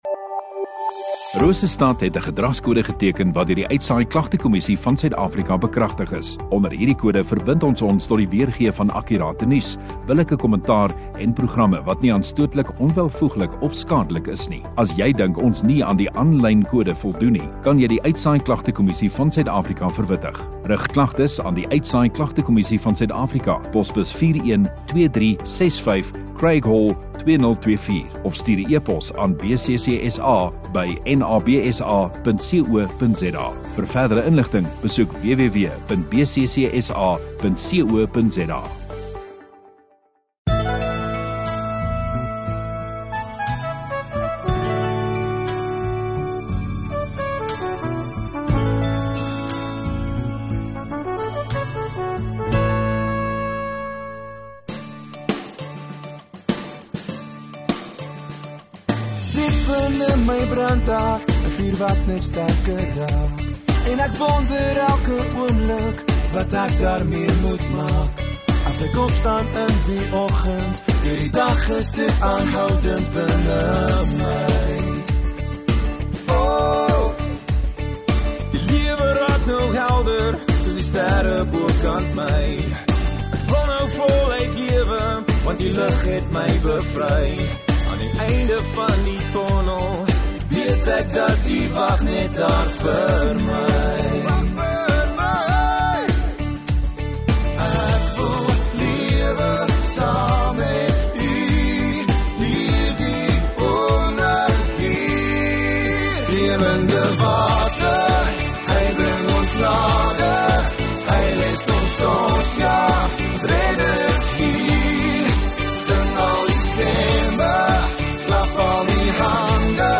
21 Jun Saterdag Oggenddiens